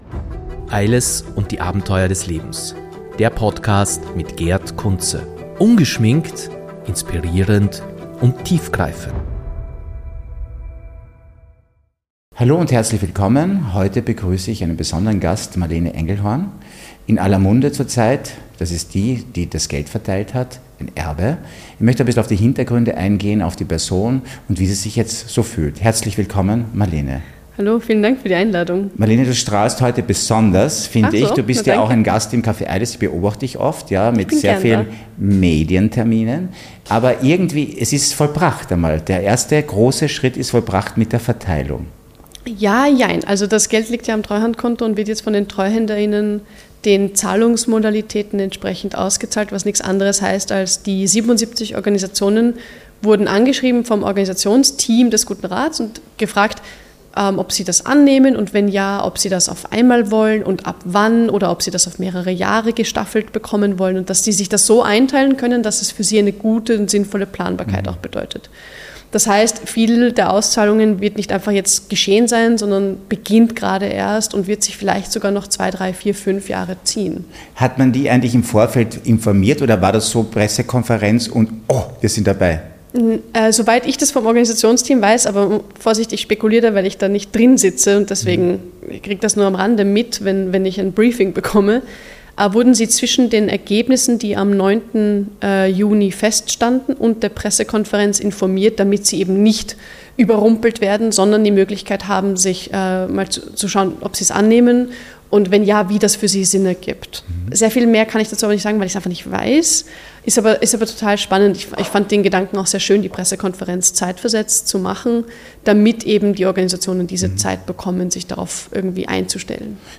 Sie erklärt, wie die Verteilung ihres Vermögens organisiert wurde, welche Rolle der „gute Rat“ dabei spielte und warum sie der Meinung ist, dass hohe Vermögen stärker besteuert werden sollten. Sie teilt außerdem ihre Gedanken zu Themen wie Klimagerechtigkeit, sozialer Gerechtigkeit und der Rolle des Kapitals in unserer Gesellschaft. Ein faszinierendes und lehrreiches Gespräch, das zum Nachdenken anregt und zeigt, wie individuelle Entscheidungen einen gesellschaftlichen Wandel anstoßen können.